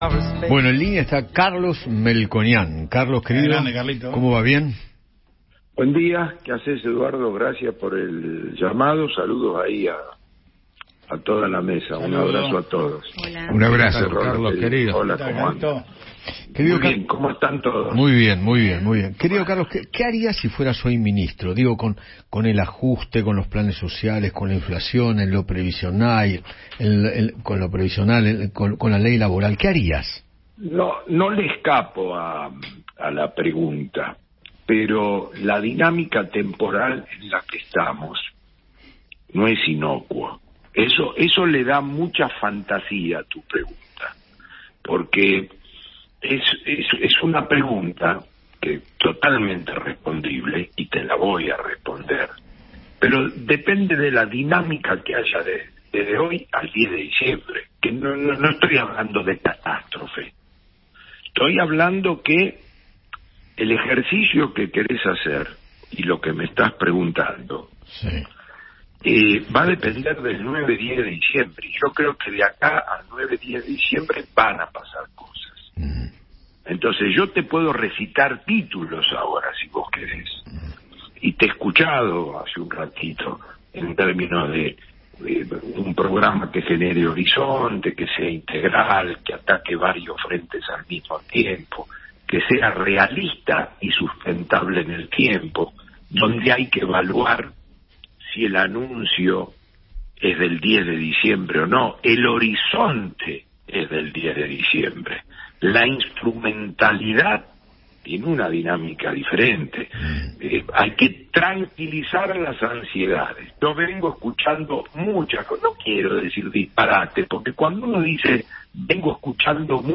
El economista Carlos Melconian habló con Eduardo Feinmann sobre el presente financiero de la Argentina.